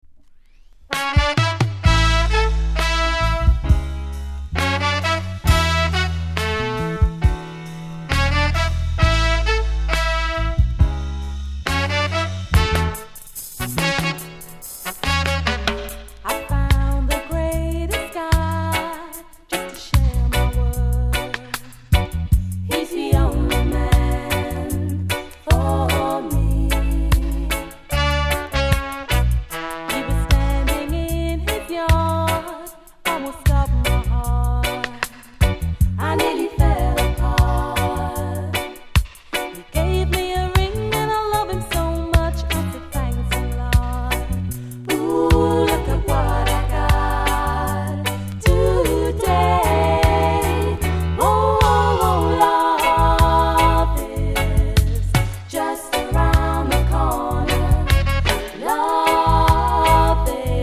※小さなチリノイズが少しあります。